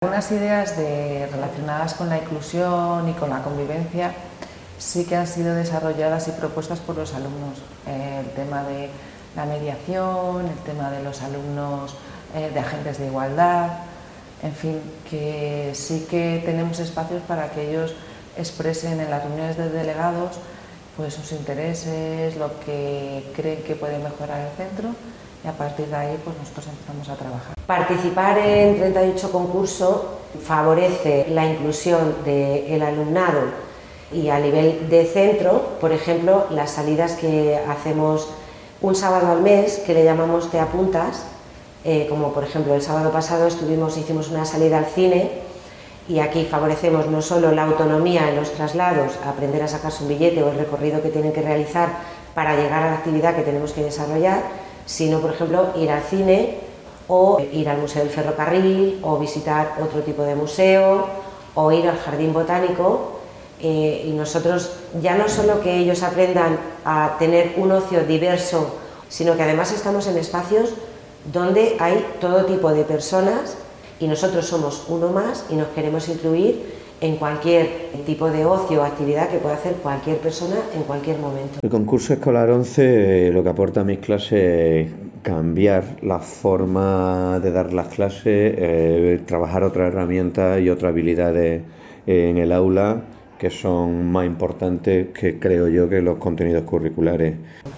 lo explican tres docentes